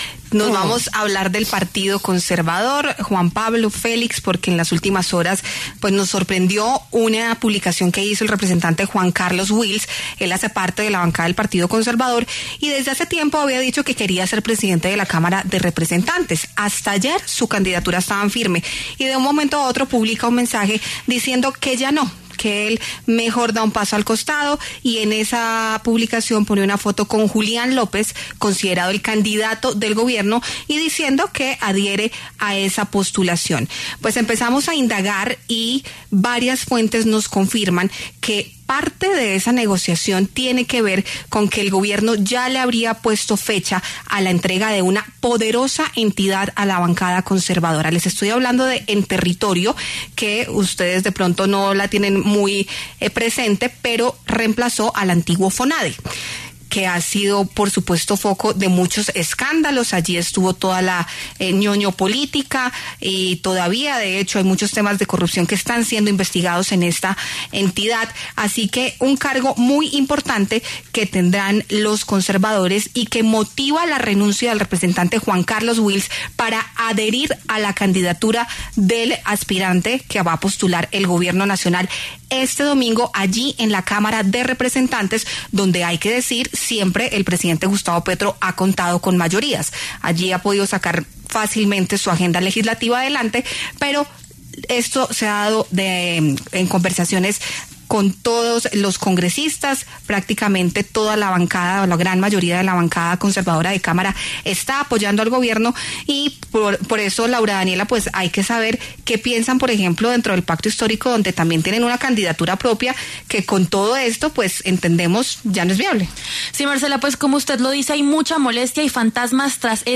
Los representantes y aspirantes a la presidencia de la Cámara Leonardo Rico, de Cambio Radical y Alejandro Ocampo, del Pacto Histórico, pasaron por los micrófonos de La W. Rico sugirió que se le entregó un puesto al Partido Conservador en la Aeronáutica Civil.